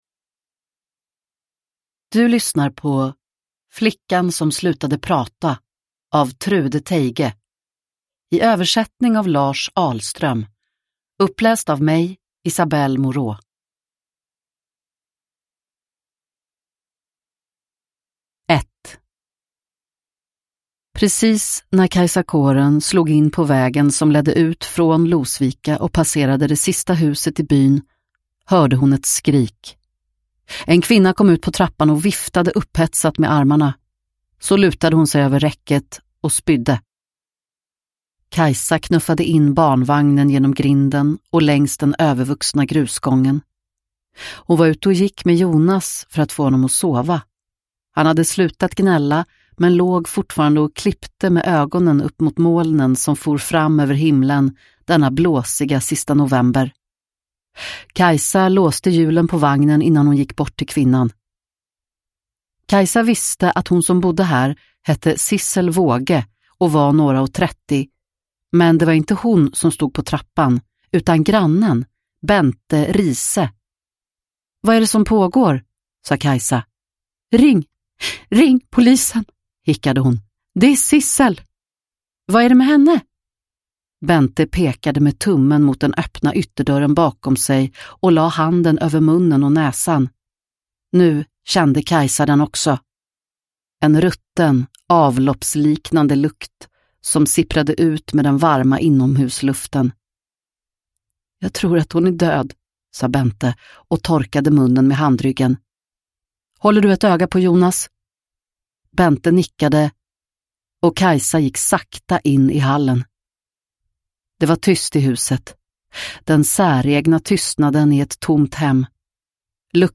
Flickan som slutade prata – Ljudbok – Laddas ner